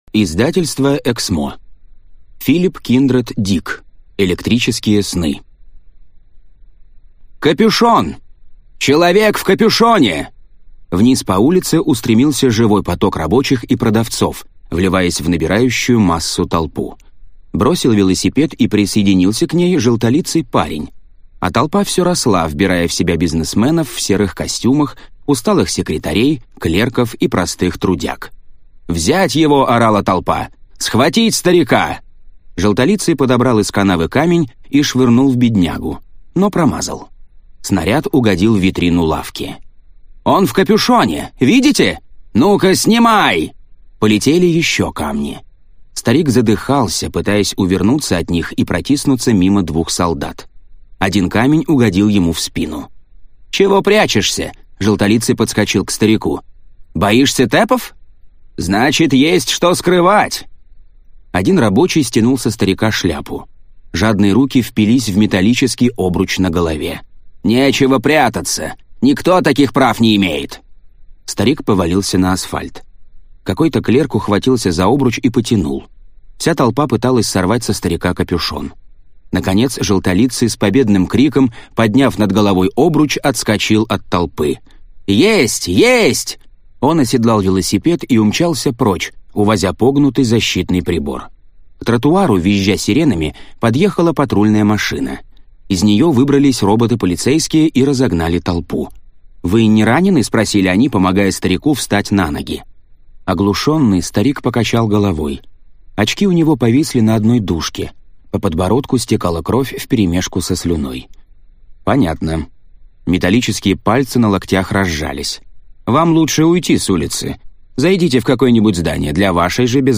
Аудиокнига Электрические сны (сборник) | Библиотека аудиокниг